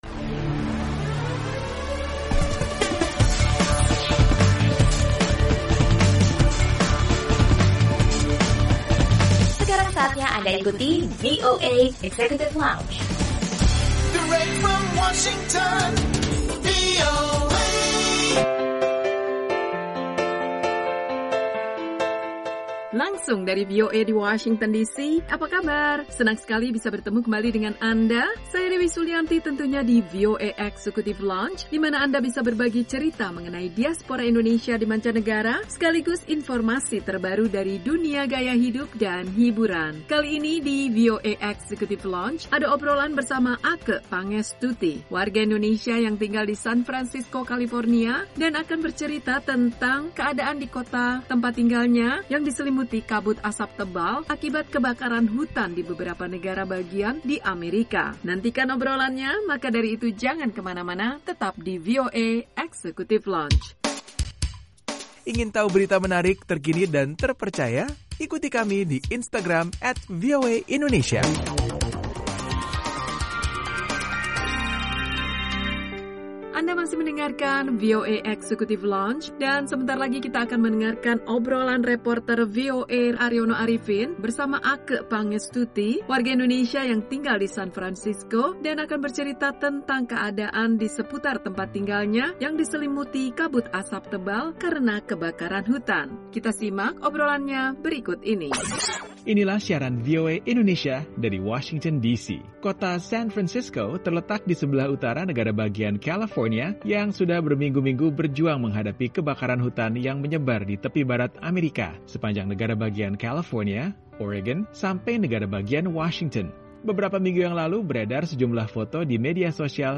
Obrolan reporter